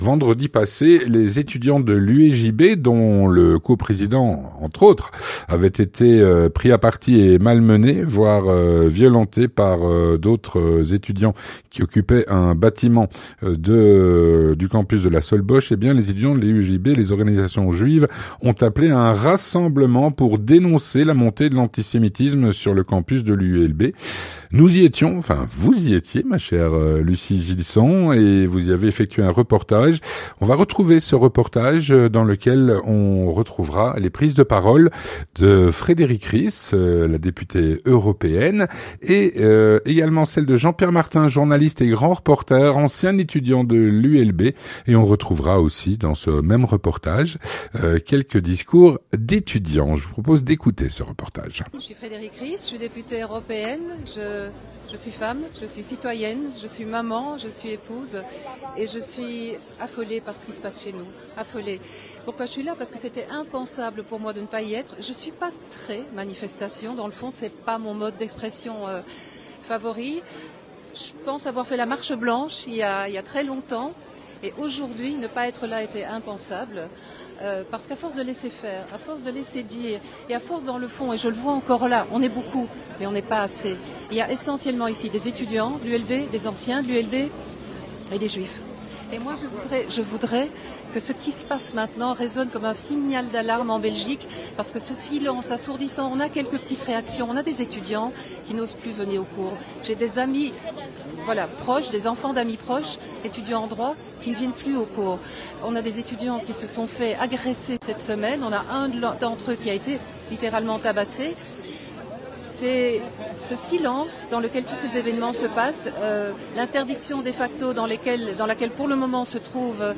Avec Frédérique Ries (eurodéputée)